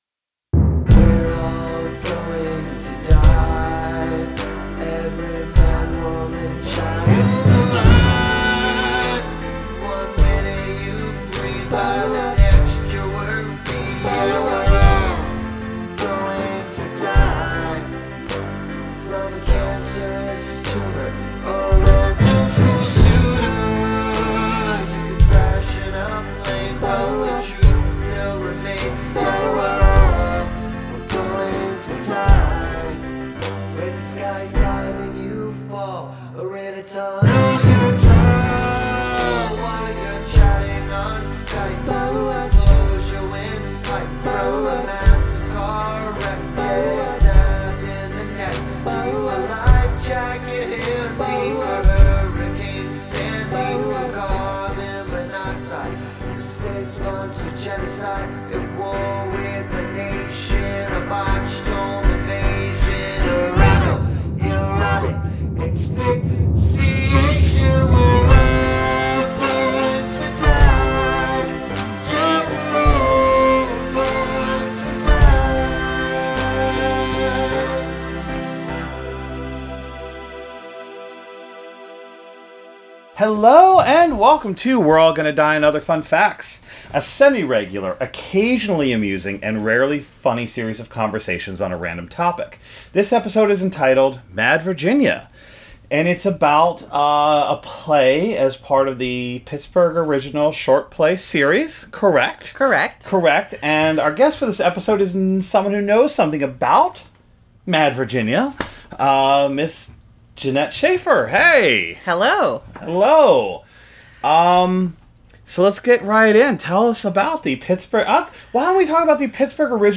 In an attempt to forge new frontiers of podcastdom We’re All Gonna Die tries our hand at podcasting over the phone, and I’m sorry about the constant hum.